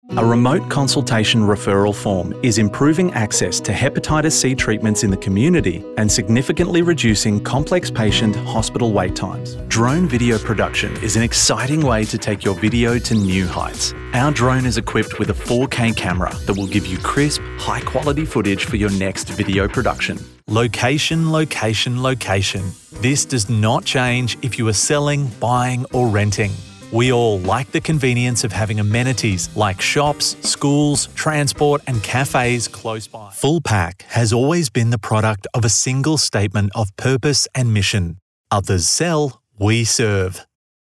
Male
English (Australian)
Yng Adult (18-29), Adult (30-50)
My natural voice tone is warm and friendly, with a casual, corporate, energetic or uplifting style, as required, suiting all types of projects.
Audiobooks
Soft-Sell Natural Speaking
Words that describe my voice are Warm, Casual, Corporate.
All our voice actors have professional broadcast quality recording studios.